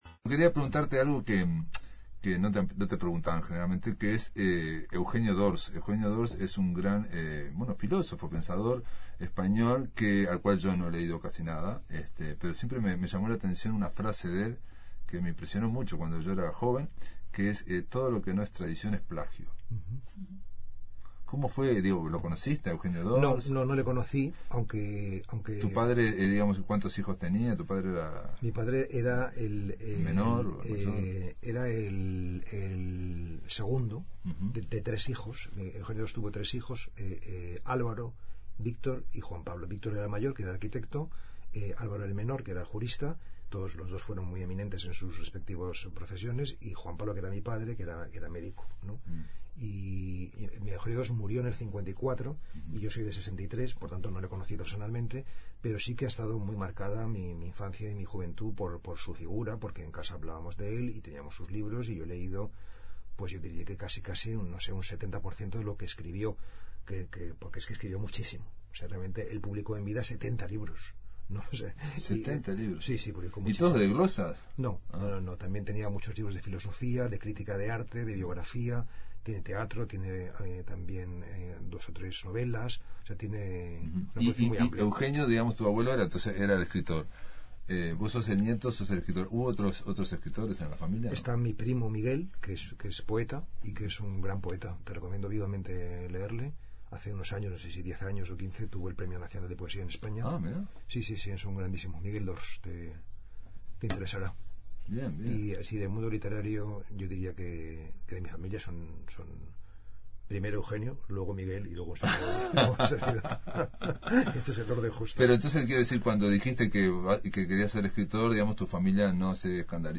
Conversamos con el escritor español Pablo D’Ors de gira por Uruguay. D’Ors es autor de, entre otros libros, Biografía del silencio, Los contemplativos o Biografía de la luz.